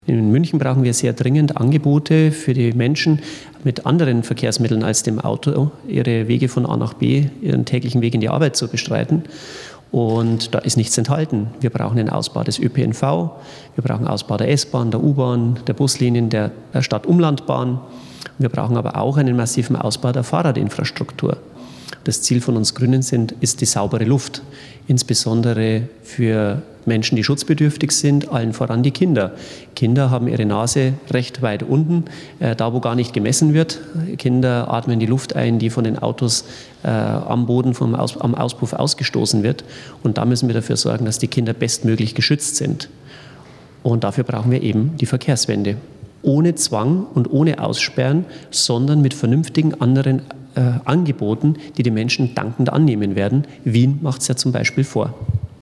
Einen sendefähigen O-Ton von Dr. Markus Büchler, zu Ihrer freien Verwendung, finden Sie hier.